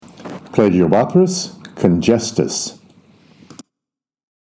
Pronunciation/Pronunciación:
Pla-gi-o-bó-thrys con-gés-tus